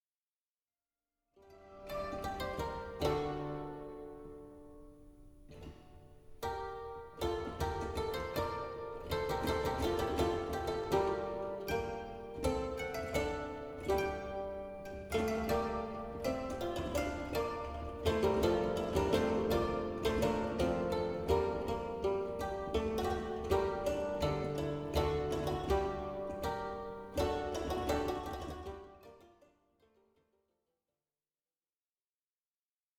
harpe, vièle, luth, rebec et clavicythérium
instr.